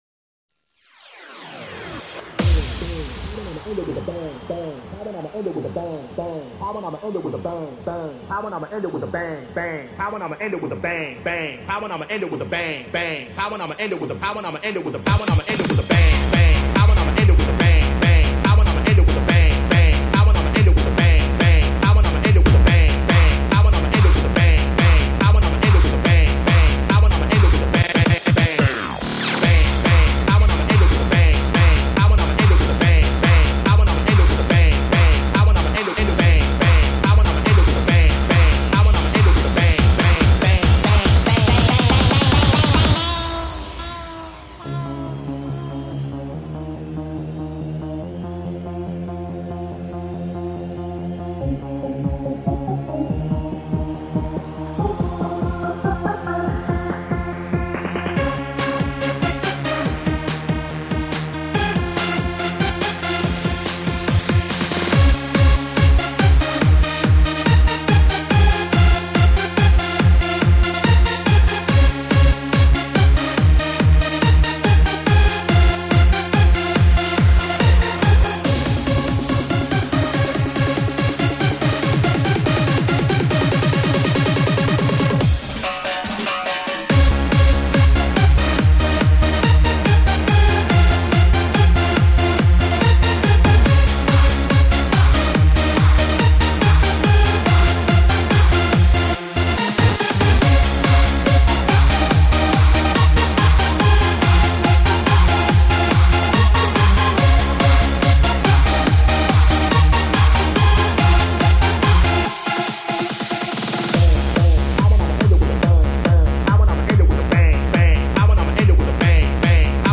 Список файлов рубрики ¤Techno Dance¤
друзья! это (tehno) под новый год самое то!